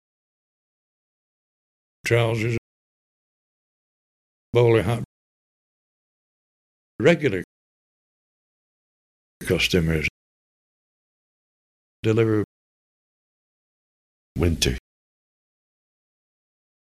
Rhotic informants born in the 1920s, 1930s and 1950s, and one nonrhotic informant born in the 1970s.
Listen to rhotic and nonrhotic examples from Preston and Blackburn:
Example 10: Rhotic weak syllables
blackburnrhoticletter.mp3